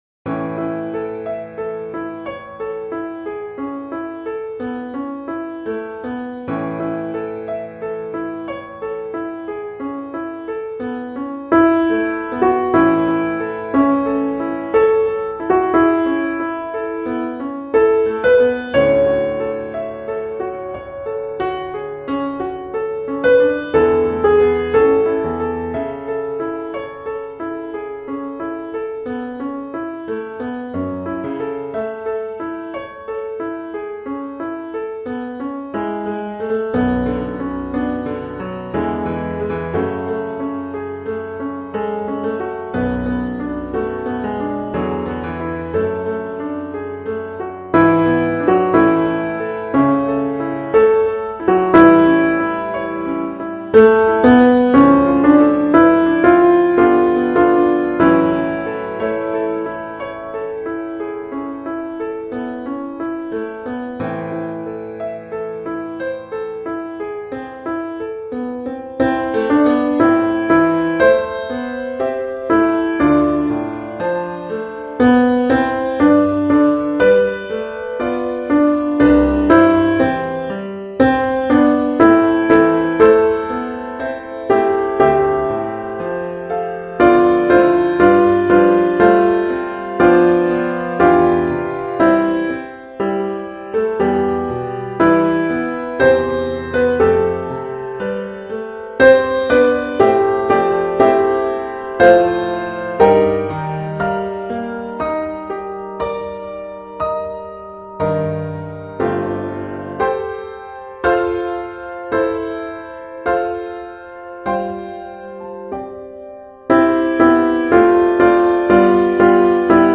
Let Me Hide Myself in Thee – Alto